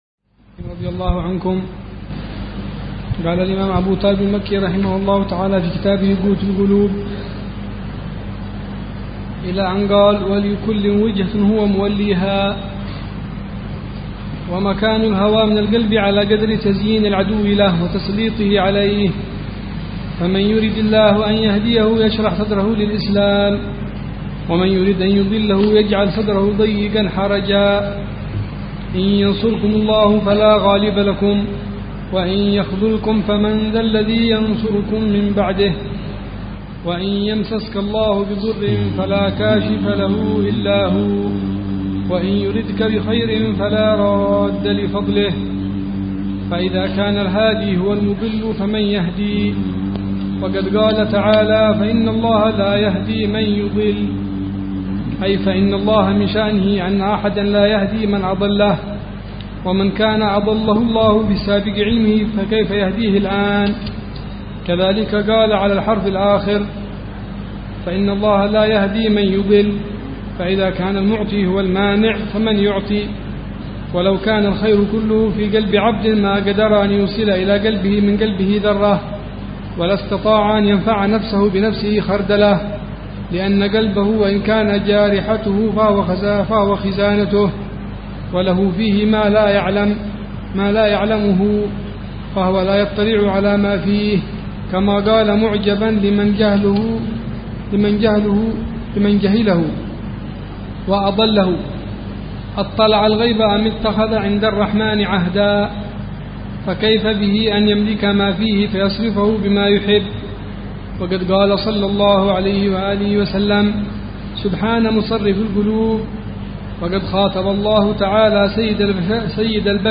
قراءة بتأمل وشرح لمعاني كتاب قوت القلوب للشيخ: أبي طالب المكي ضمن دروس الدورة التعليمية السادسة عشرة بدار المصطفى 1431هجرية.